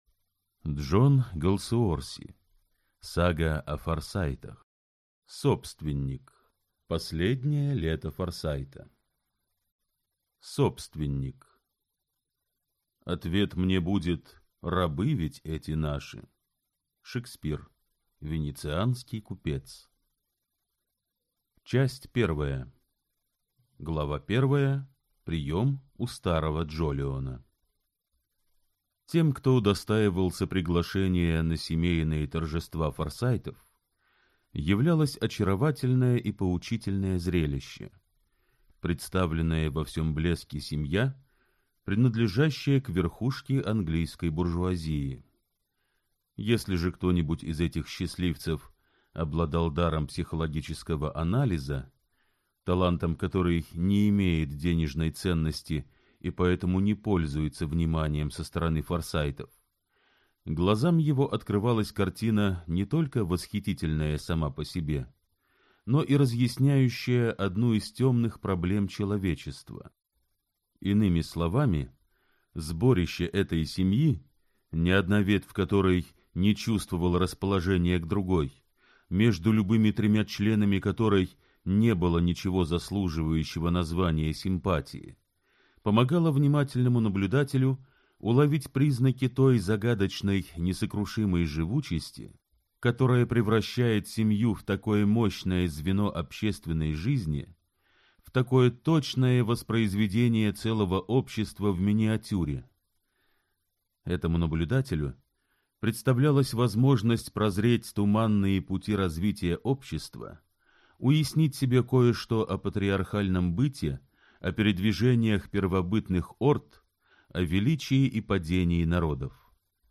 Аудиокнига Сага о Форсайтах. Собственник. Последнее лето Форсайта | Библиотека аудиокниг